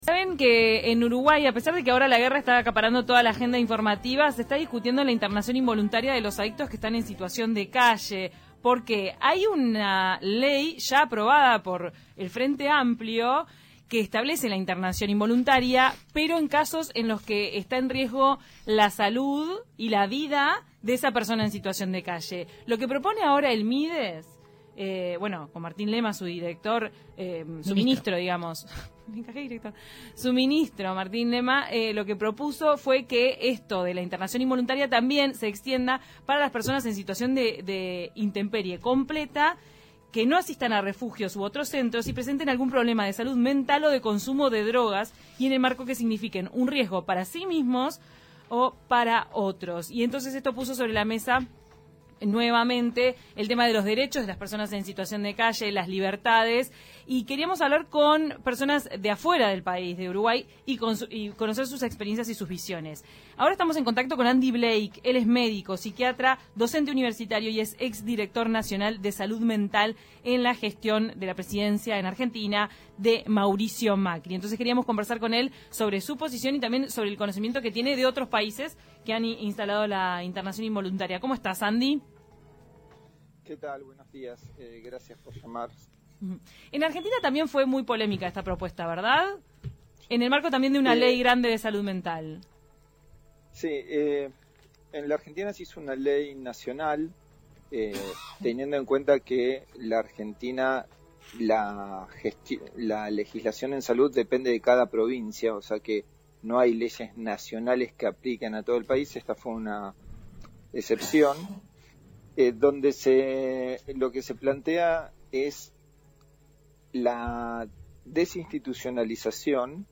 Estuvimos en contacto con Andy Blake, es médico, psiquiatra y docente universitario, además es exdirector Nacional de Salud Mental de Argentina en la gestión de Mauricio Macri.
Al respecto de la internación involuntaria, los derechos de las personas en situación de calle y las libertades, Andy Blake nos contó sus experiencias y visiones. Escuchá la entrevista completa aquí: